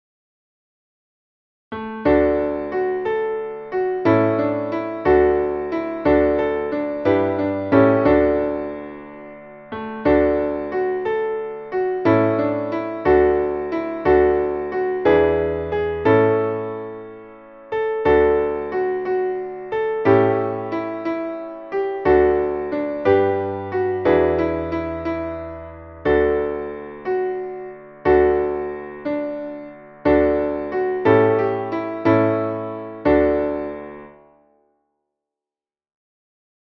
Traditionelles Wiegen-/ Weihnachtslied